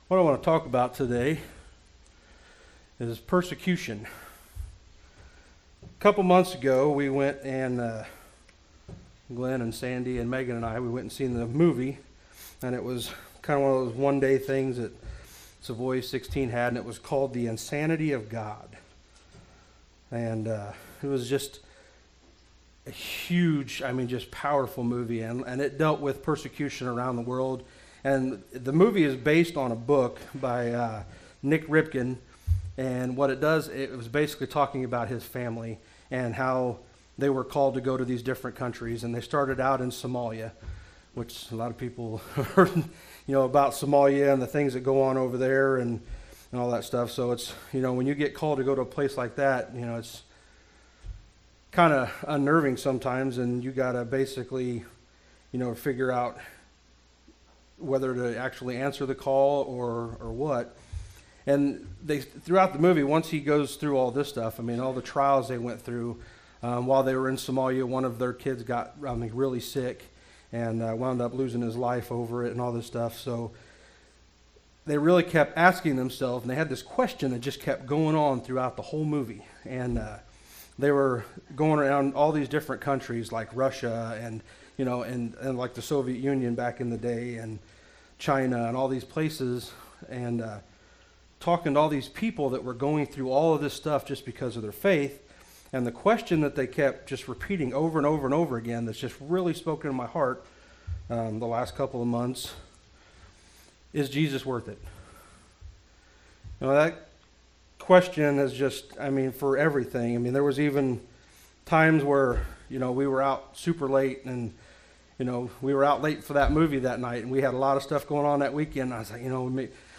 "2 Timothy 3:10-12" Service Type: Sunday Morning Worship Service Bible Text